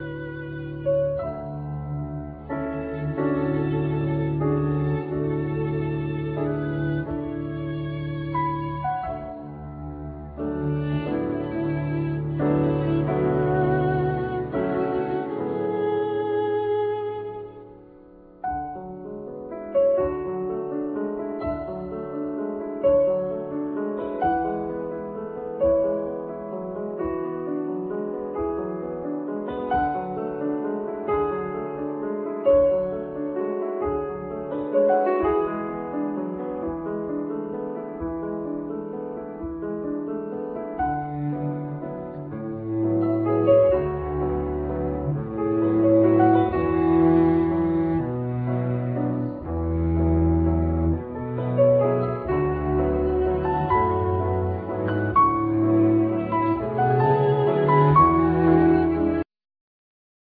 Piano
Viola
Cello